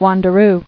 [wan·der·oo]